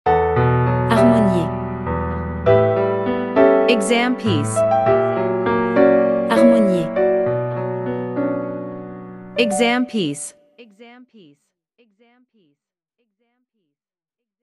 • 人声数拍
我们是钢琴练习教材专家